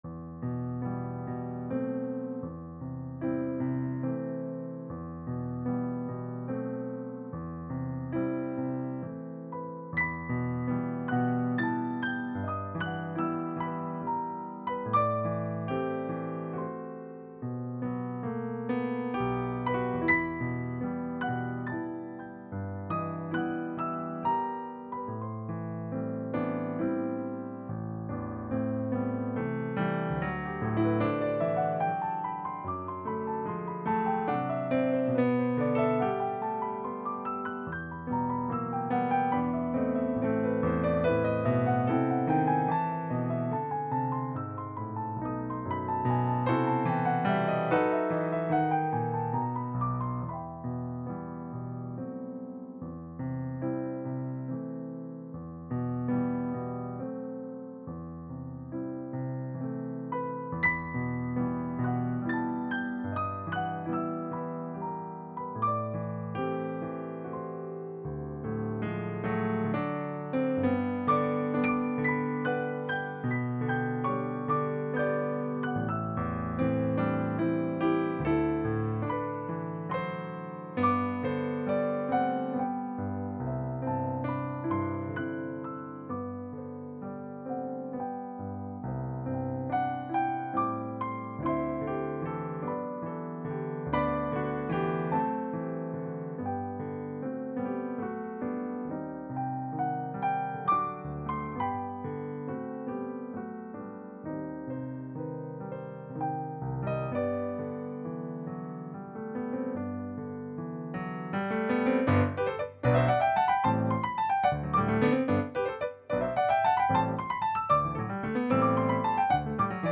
Voicing: Piano Duet